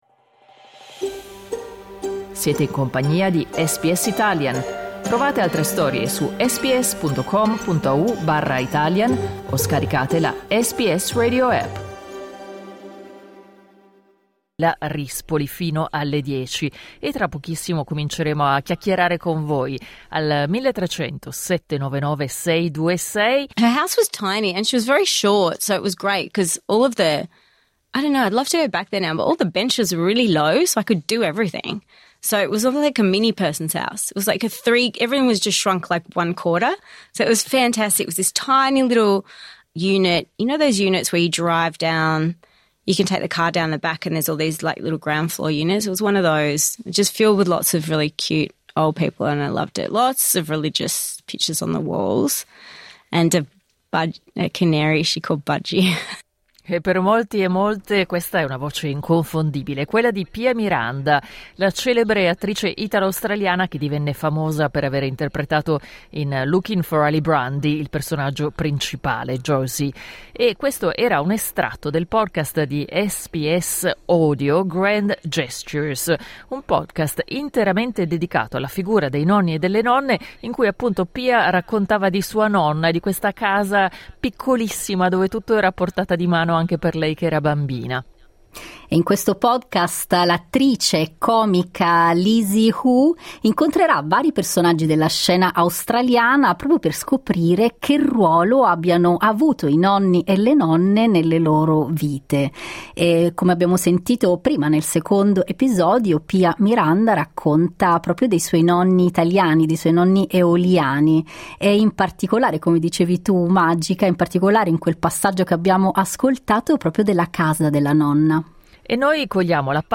Clicca sul tasto "play" in alto per ascoltare le voci delle ascoltatrici Nonno e nipote.